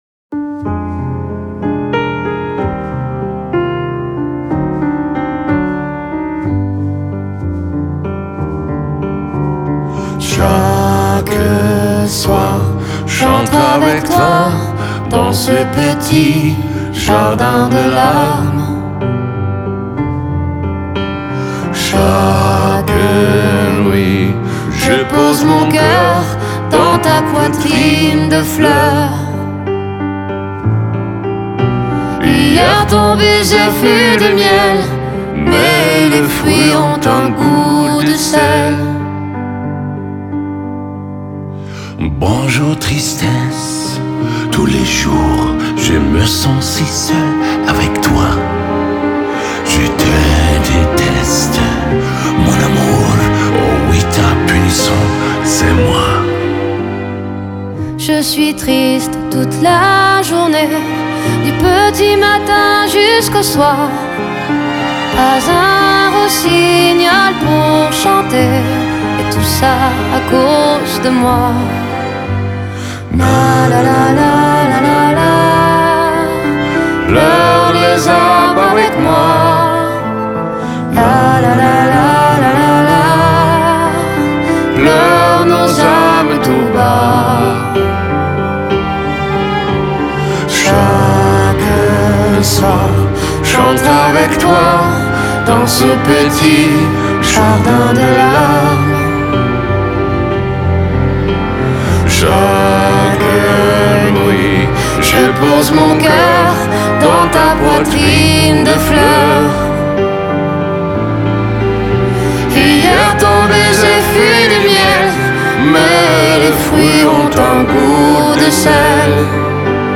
с потрясающим тембром